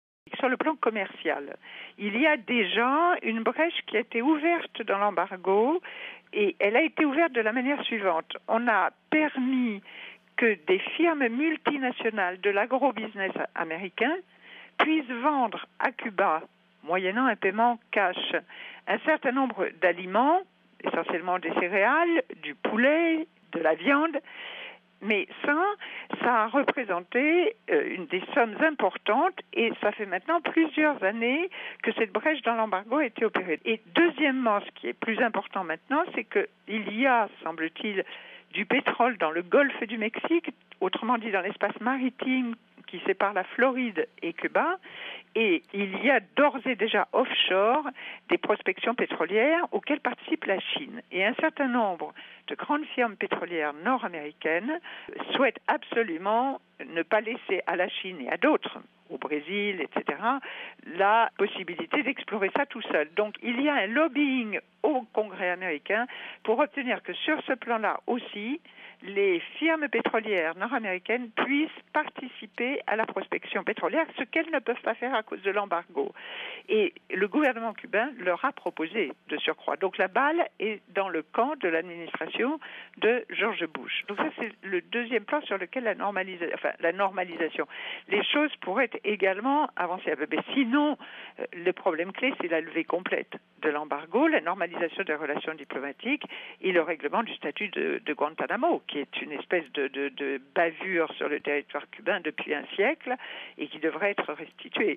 L’entretien